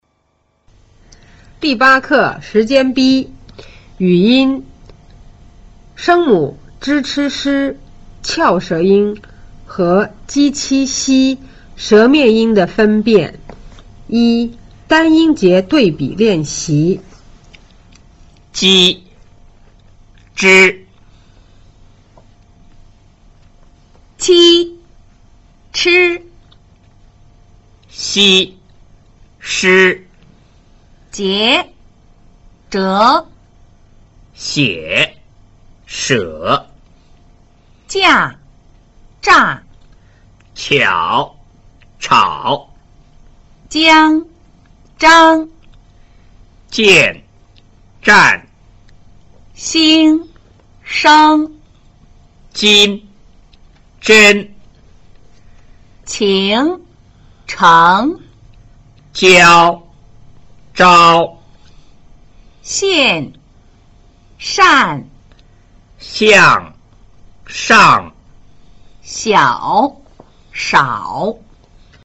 聲母 zh ch sh 翹舌音 和 j q x 舌面音的分辨
1. 單音節對比練習